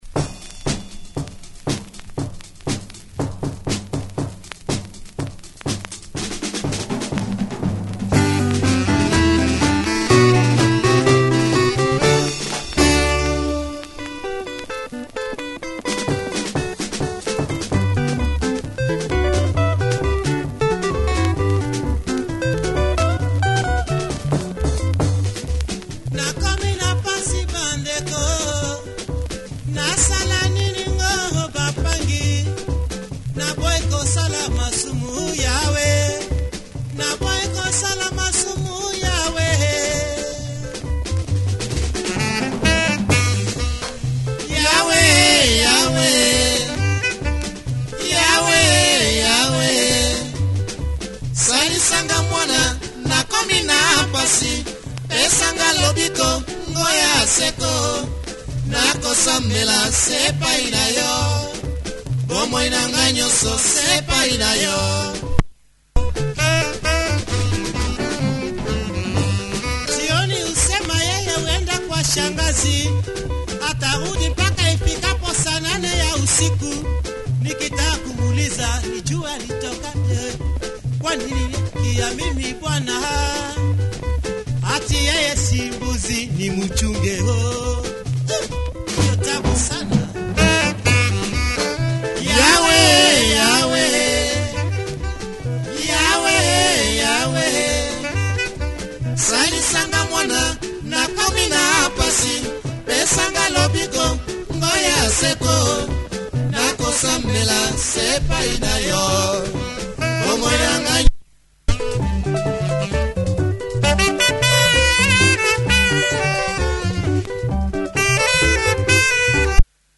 great drive especially in part-2